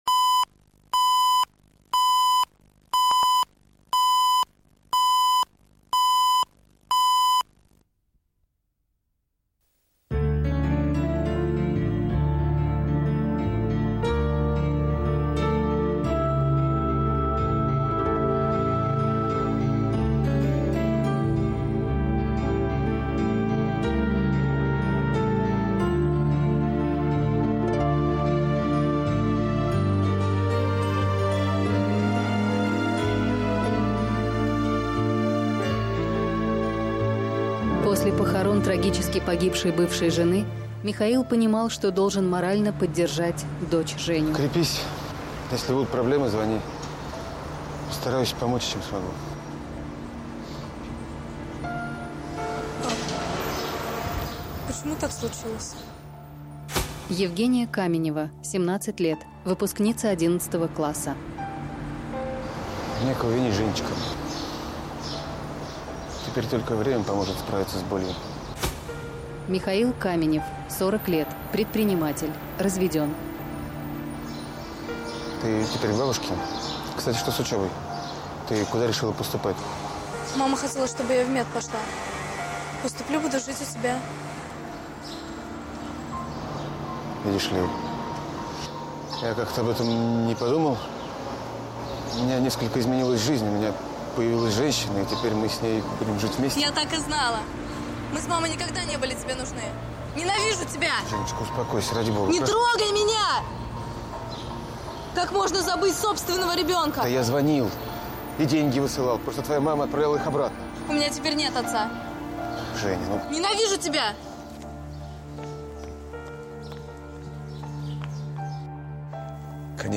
Аудиокнига Лишние хлопоты